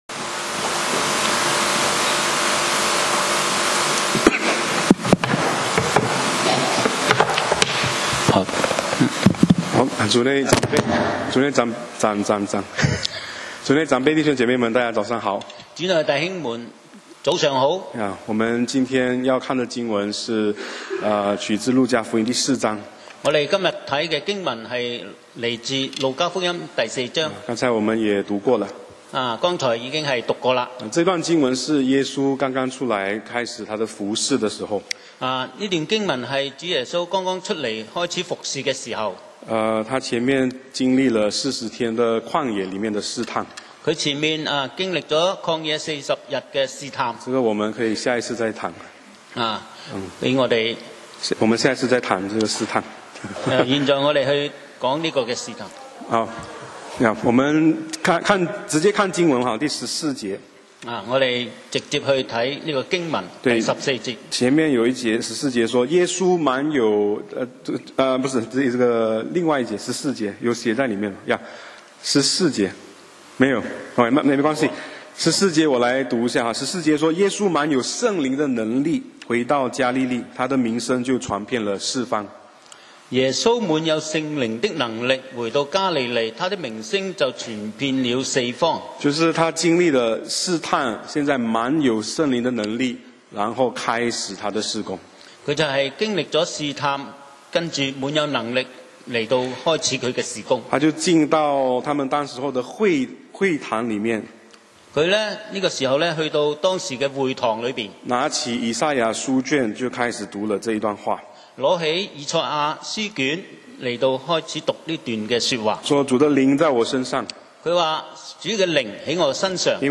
講道 Sermon 題目 Topic：自己家乡的先知 經文 Verses：路加福音4:21-30. 21耶稣对他们说，今天这经应验在你们耳中了。